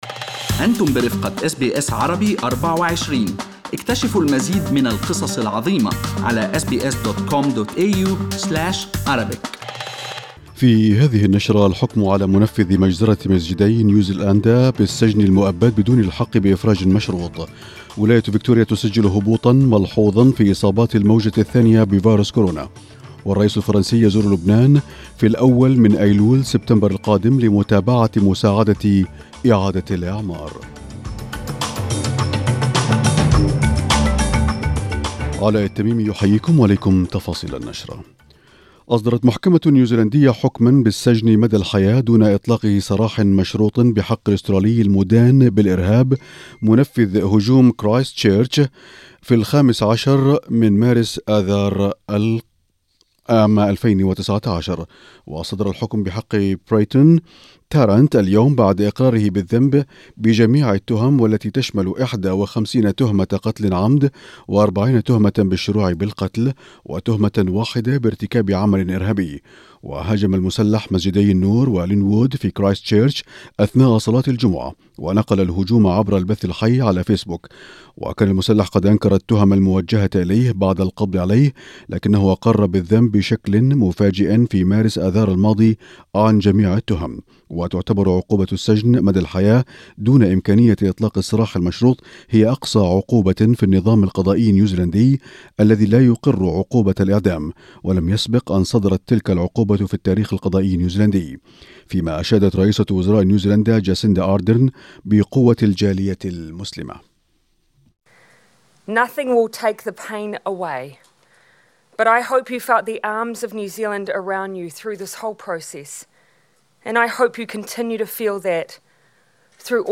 نشرة أخبار المساء27/8/2020